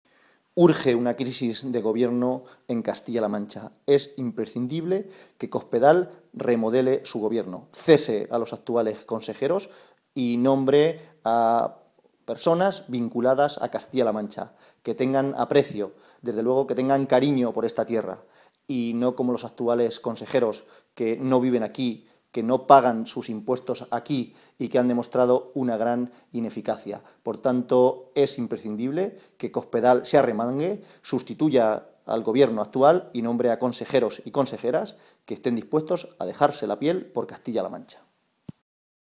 José Manuel Caballero, secretario de Organización del PSOE de Castilla-La Mancha
Cortes de audio de la rueda de prensa